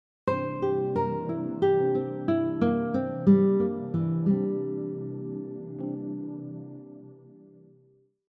For example 2, we have a descending arpeggio pattern that skips one note after every chord tone.
Dominant 7 arpeggio example 2
Dominant-7-arpeggio-example-2.mp3